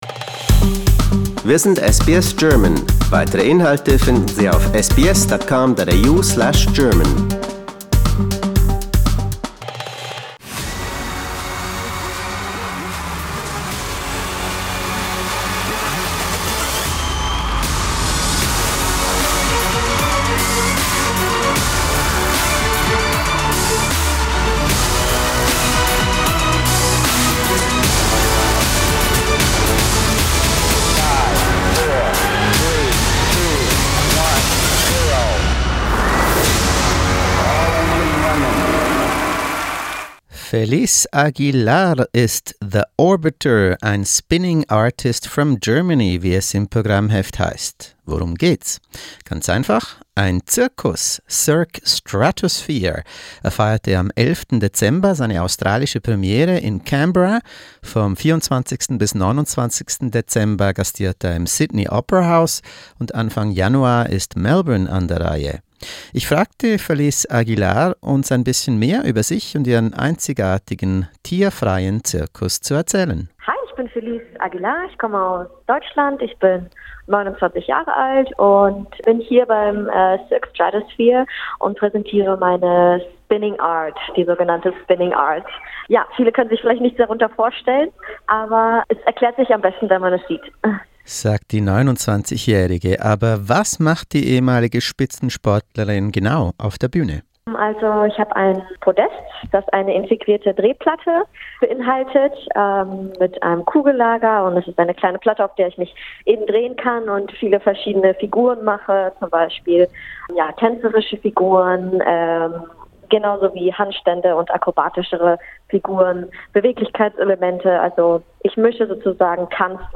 Cirque Stratosphere: Interview with a German performer